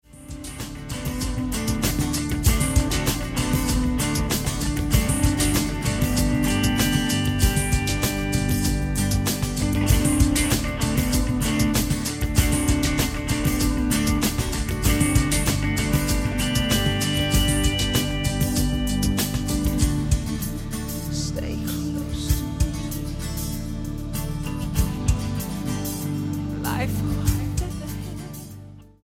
STYLE: Rock
hook ridden catchiness